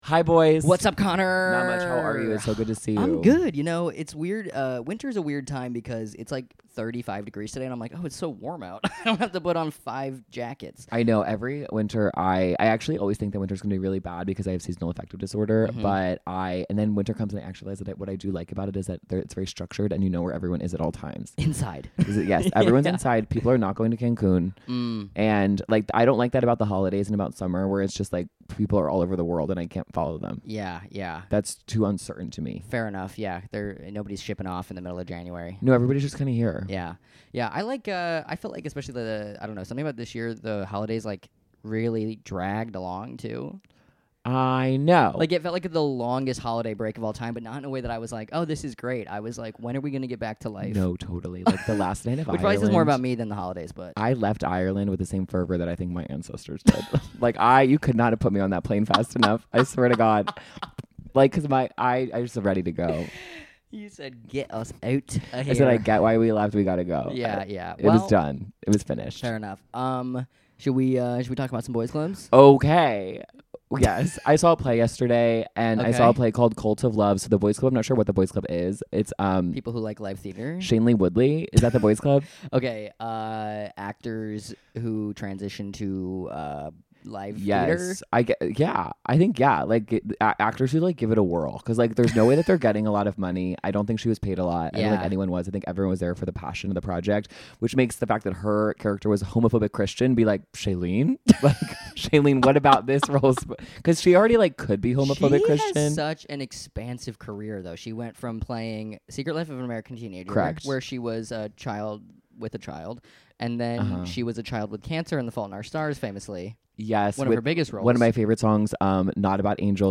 A podcast from two dudes who were never part of the "boys club" and want to invite you into theirs.